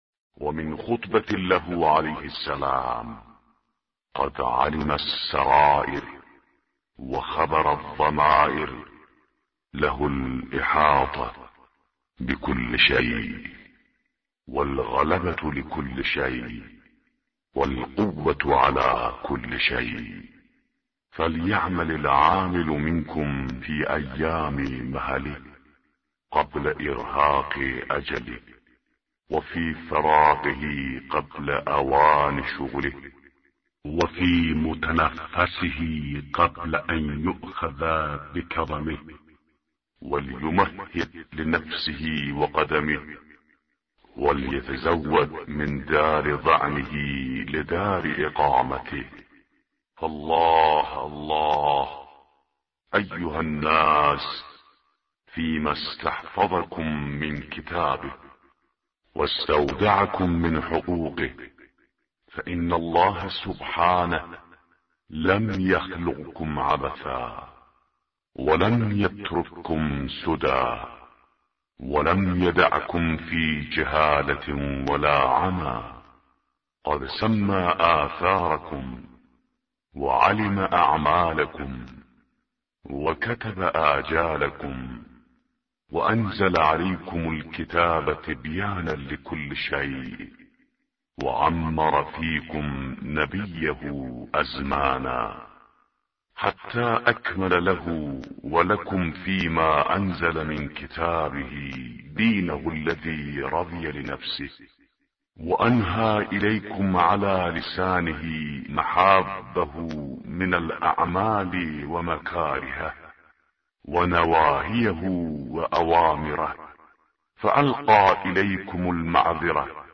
به گزارش وب گردی خبرگزاری صداوسیما؛ در این مطلب وب گردی قصد داریم، خطبه شماره ۸۶ از کتاب ارزشمند نهج البلاغه با ترجمه محمد دشتی را مرور نماییم، ضمنا صوت خوانش خطبه و ترجمه آن ضمیمه شده است: